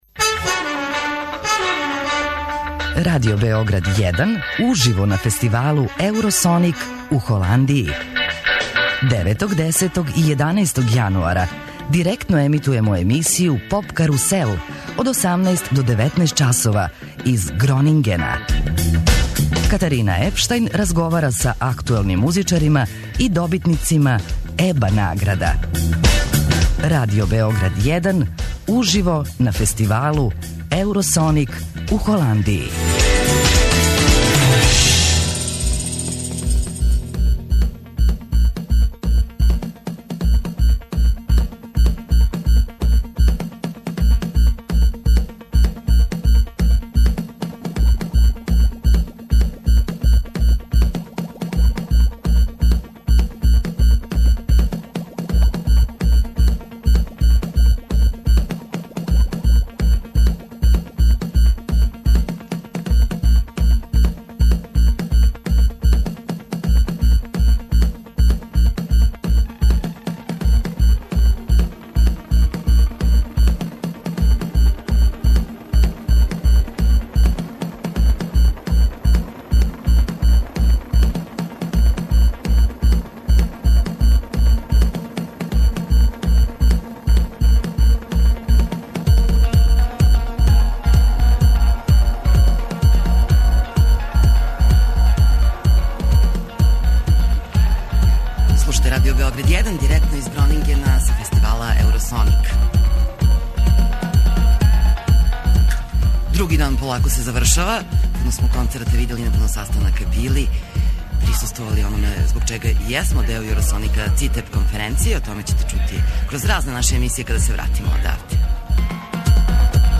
Екипа Радио Београда 1 и ове године реализује директне преносе са Eurosonic фестивала у Холандији.